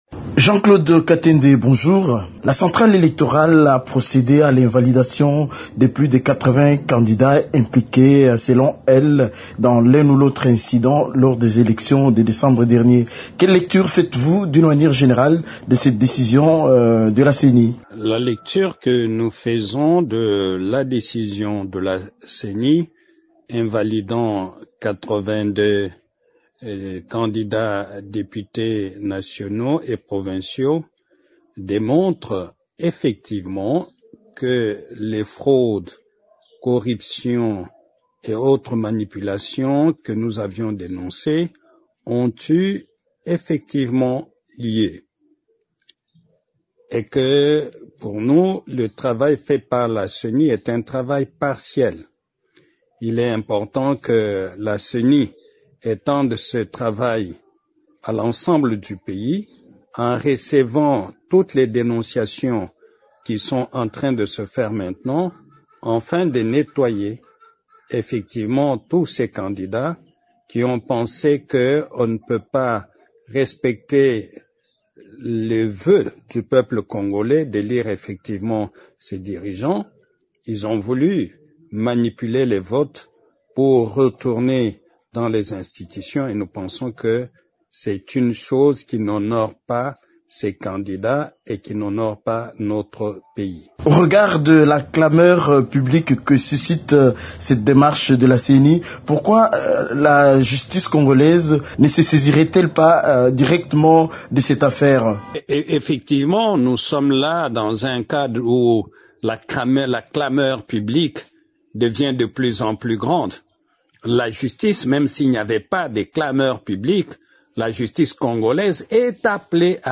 Dans une interview exclusive à Radio Okapi, il a soutenu que l’enquête sur notamment la fraude électorale dont sont accusés ces candidats devrait être menée par une institution autre que la CENI.